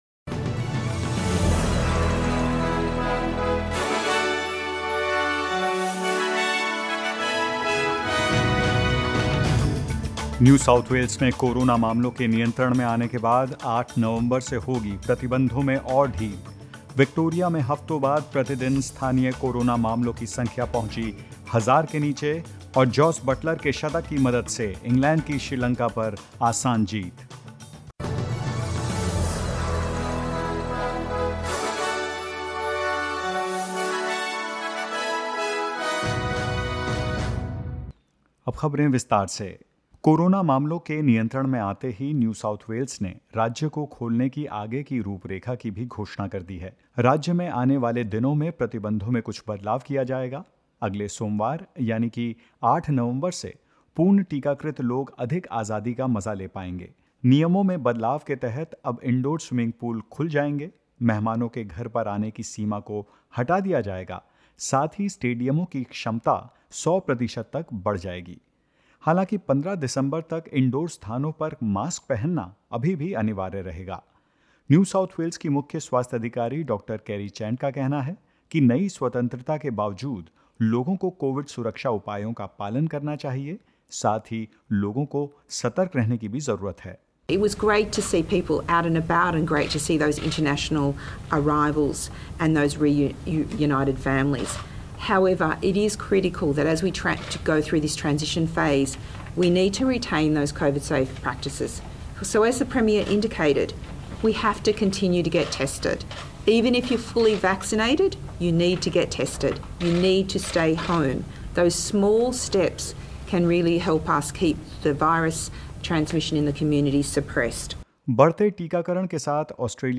In this latest SBS Hindi news bulletin of Australia and India: Victoria records 989 new community cases and nine deaths; New South Wales (NSW) records 173 new cases and four deaths and more.